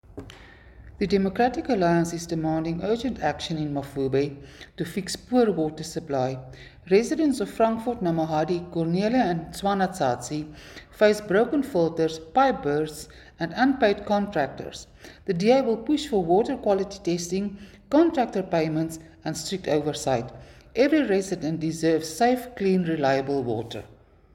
Afrikaans soundbites by Cllr Suzette Steyn and Sesotho soundbite by Cllr Kabelo Moreeng.